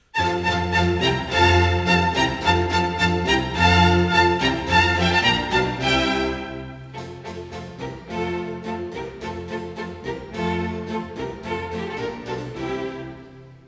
First Movement: Allegro - F Major
The opening tutti depicts 'The Countryfolk Dancing and Singing'., and is based on a three bar phrase of tonic and dominant quavers, played twice - once 'forte' then 'piano'.